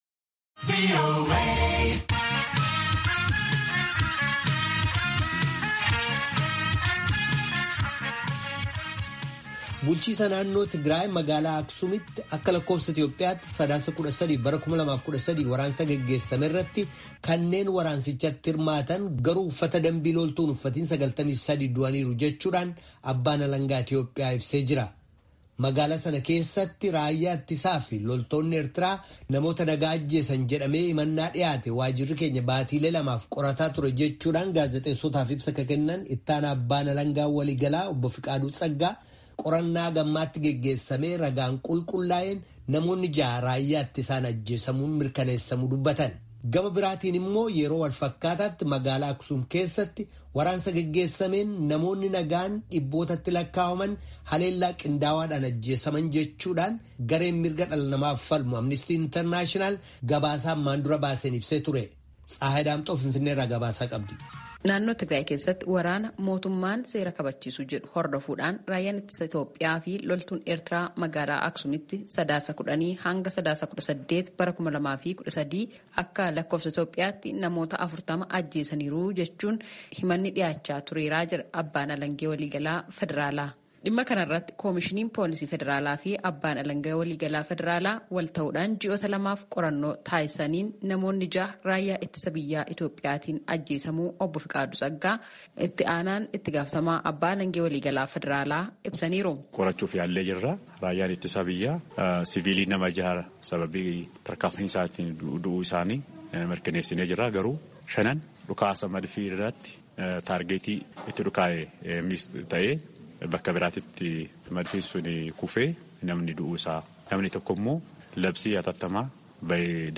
Finfinnee irraa gabaasaa qabdi.